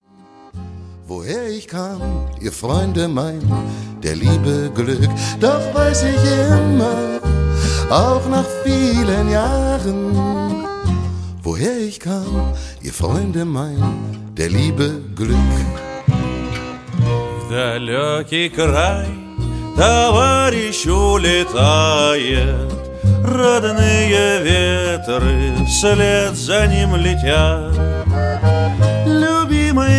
World Music From Berlin